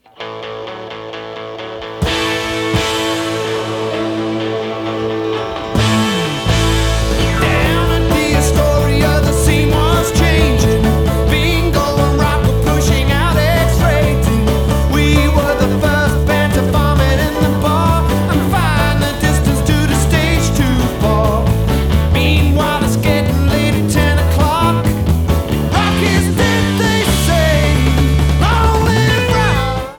Sound Samples (All Tracks In Stereo Except Where Noted)
Atmos->2.0 version